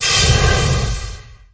Angry Birds Star Wars Steam Generator 1